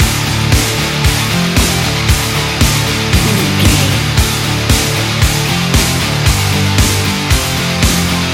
energetic, powerful and aggressive hard rock track
Fast paced
In-crescendo
Ionian/Major
industrial
driving
groovy
dark